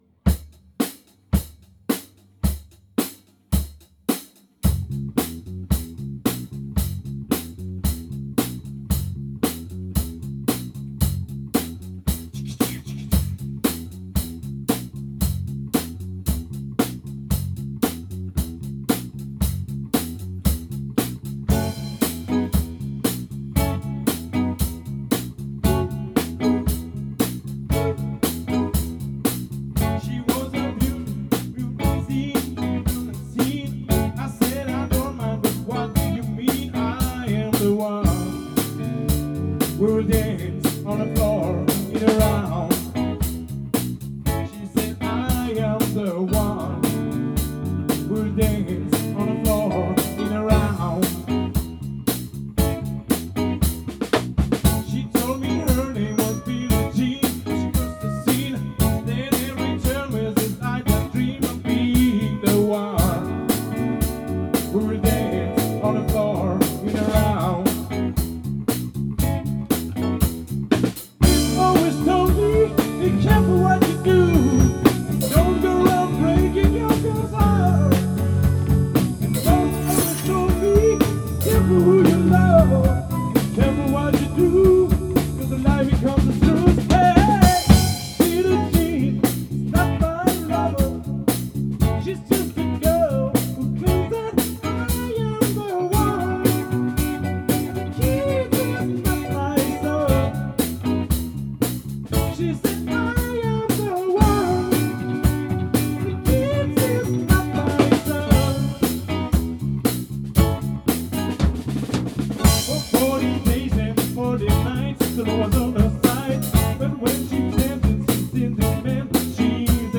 TEMPO : 117
2 mes batterie
4 mes bass/bat/clav